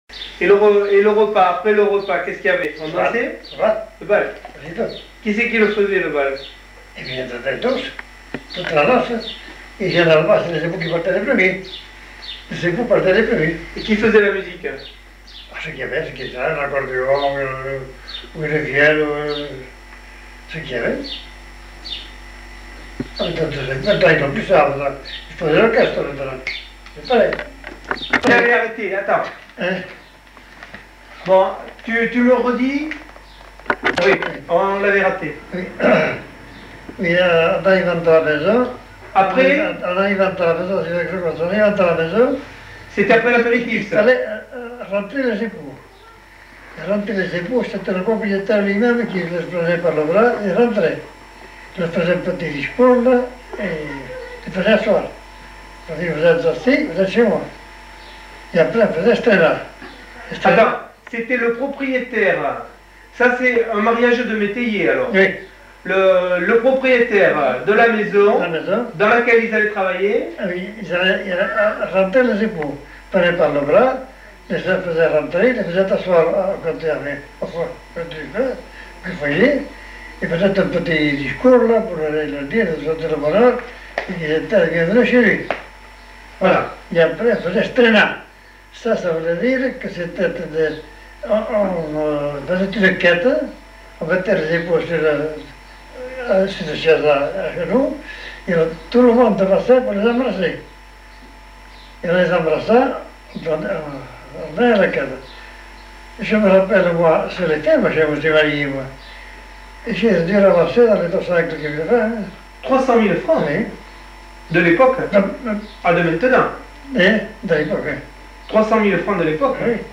Aire culturelle : Petites-Landes
Genre : témoignage thématique